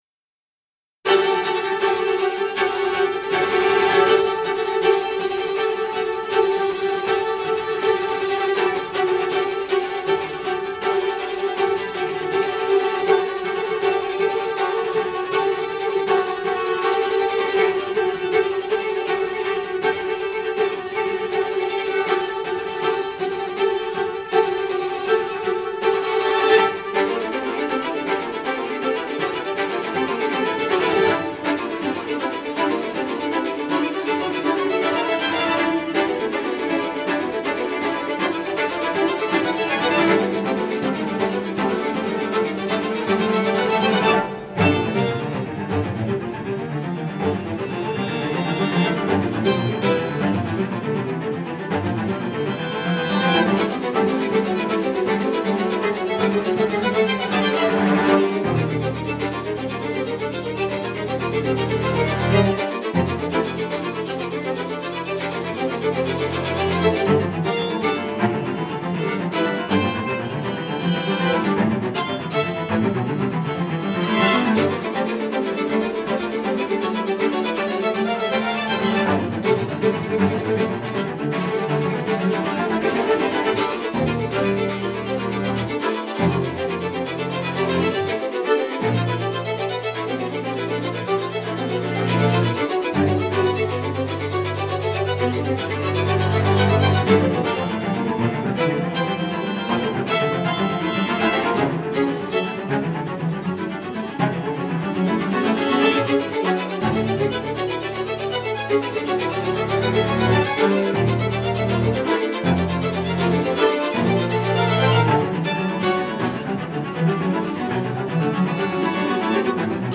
Se grabó en la Ciudad de México en el 2021.
It was recorded in 2021 in Mexico City.